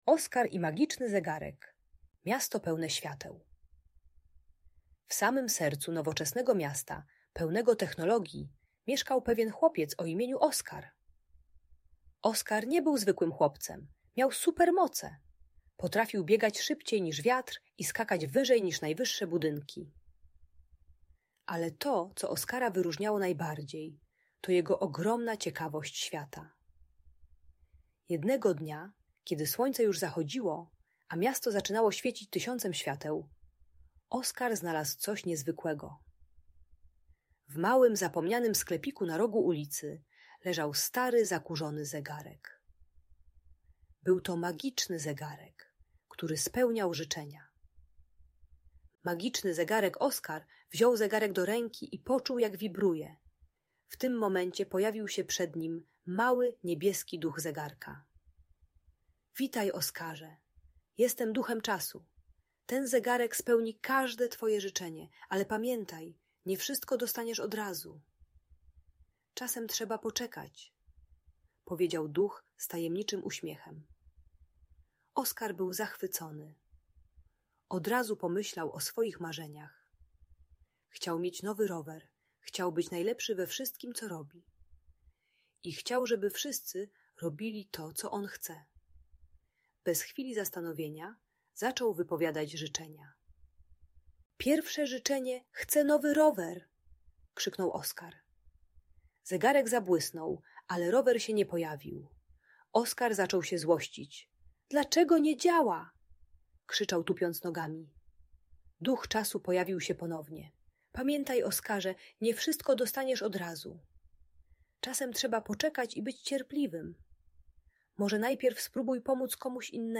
Oskar i Magiczny Zegarek - Historia o Supermocach i Mądrości - Audiobajka